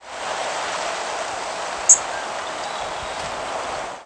Cerulean Warbler Dendroica cerulea
Flight call description A relatively low, abrupt, buzzy "dzzt".
Fig.1. Texas April 24, 2001 (MO).
Perched bird.
Similar species Very similar to Yellow, Blackpoll, and Connecticut Warblers but typically more abrupt and often with audibly deeper modulations.